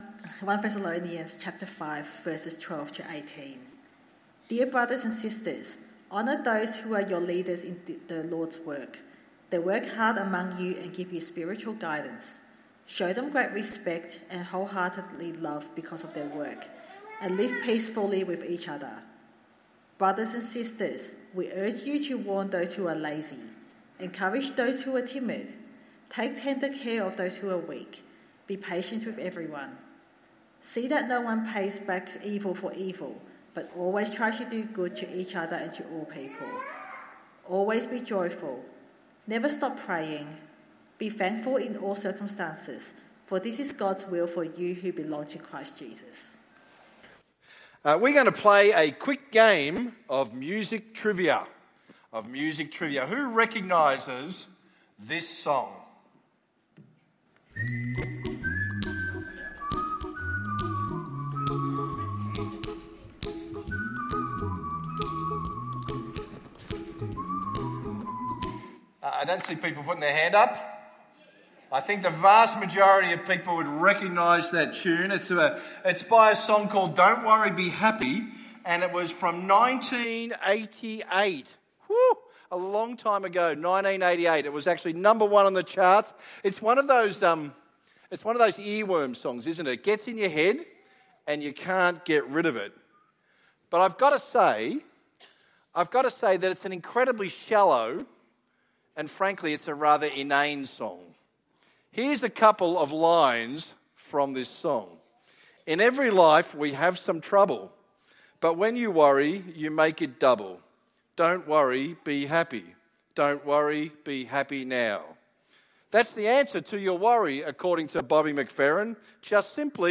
Croydon Hills Baptist Church